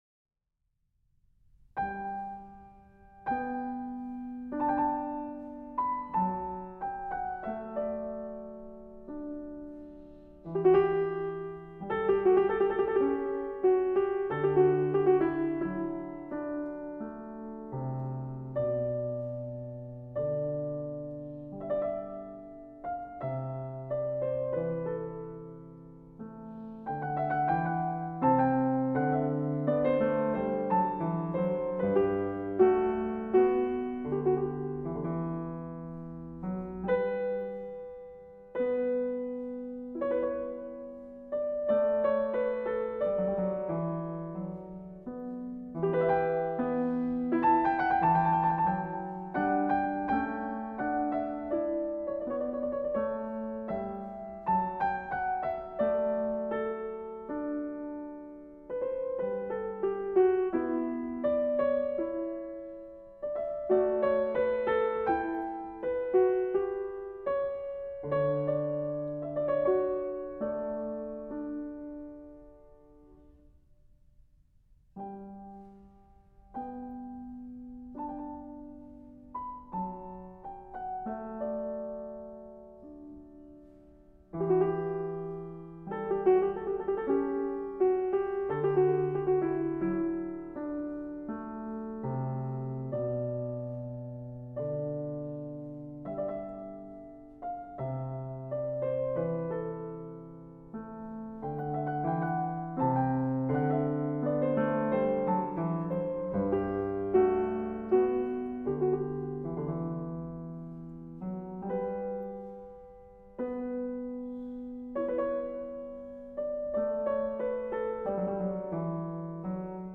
Kimiko Ishizaka - J.S. Bach- -Open- Goldberg Variations, BWV 988 (Piano) - 01 Aria